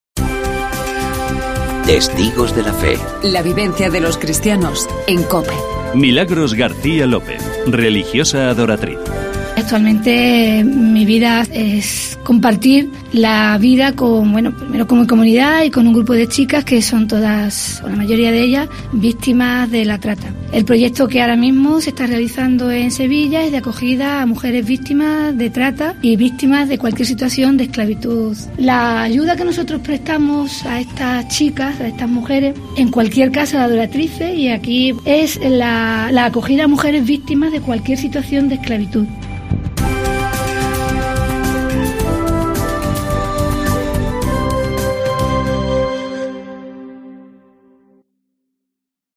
El testimonio